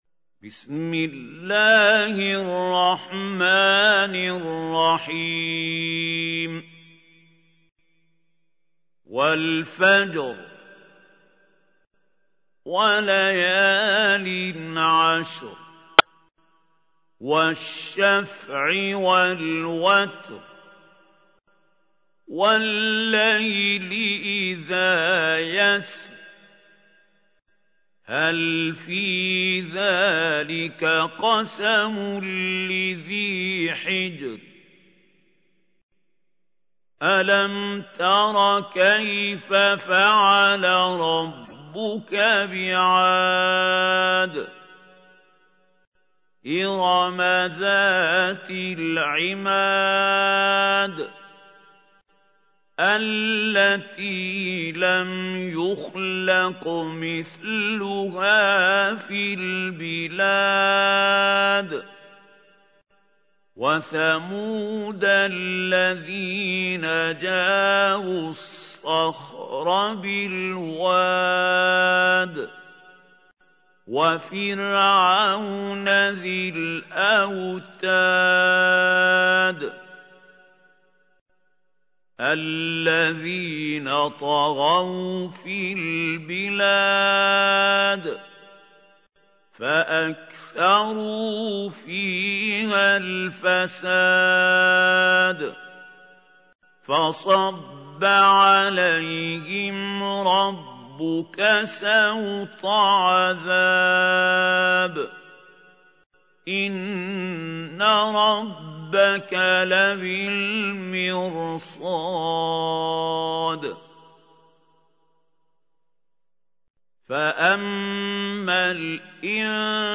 تحميل : 89. سورة الفجر / القارئ محمود خليل الحصري / القرآن الكريم / موقع يا حسين